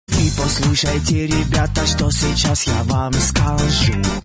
веселые
Sms сообщение